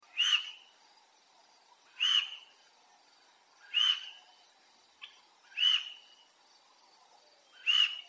Записи сделаны в дикой природе и передают атмосферу саванны.
Самец антилопы Пуку издает громкий крик